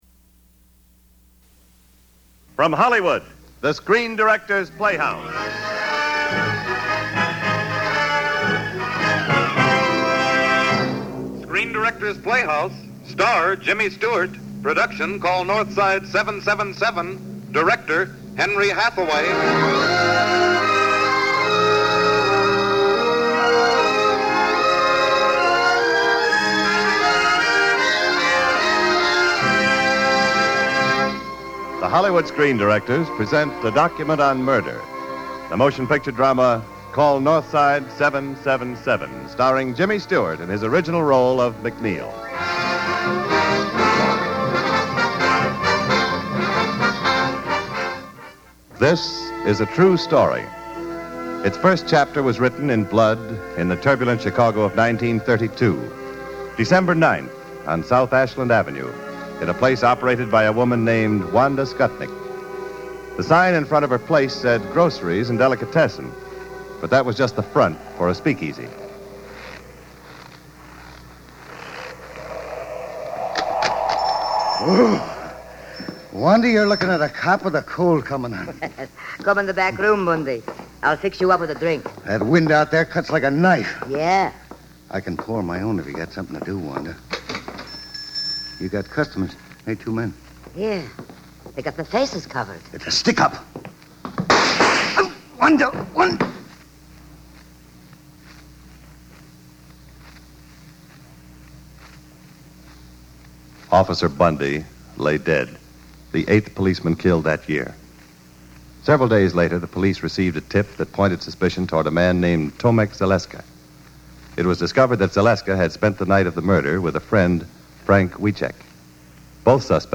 Listen: the radio adaptation By clicking on the link below you can hear the Screen Directors Playhouse radio adaptation of Call Northside 777, starring James Stewart, which originally aired live on December 9,1949 on NBC radio.